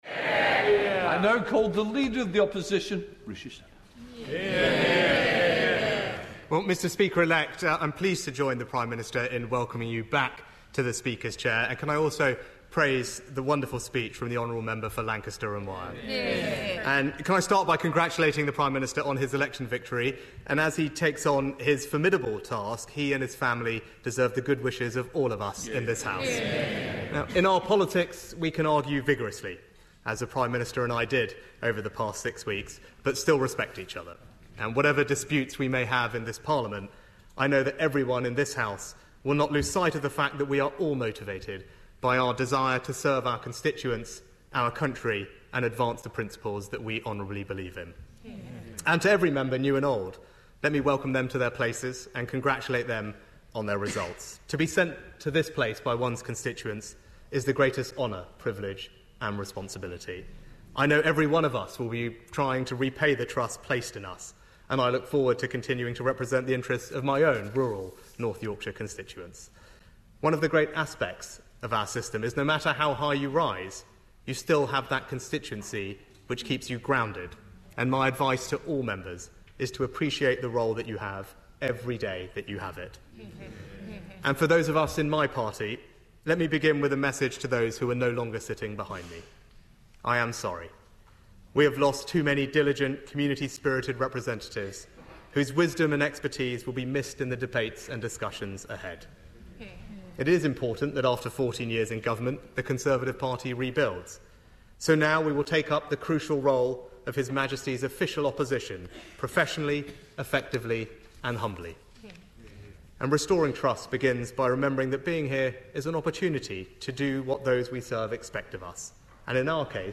delivered 9 July 2024, House of Commons, London, England
Audio mp3 of Address